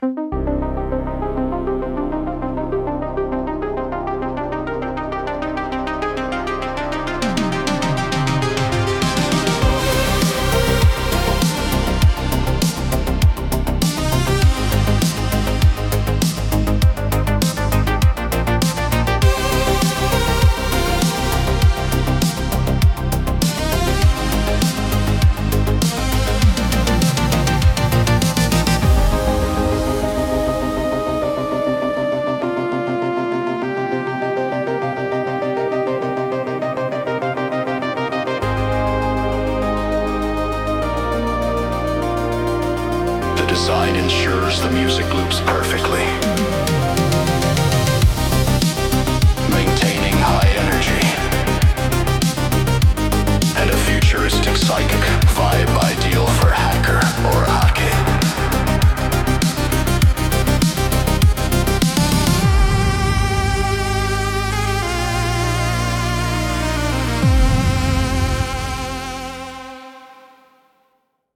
synthwave soundtrack
Genre: Synthwave / Cyberpunk / Retro Electronic